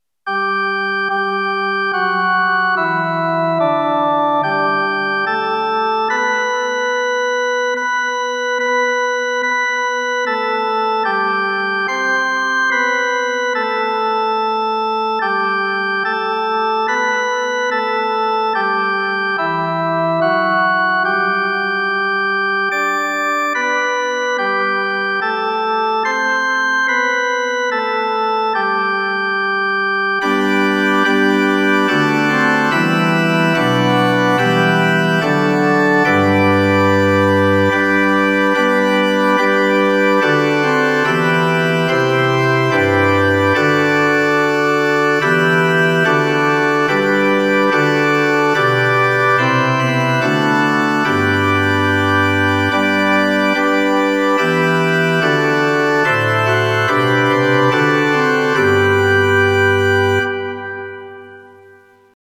Imnul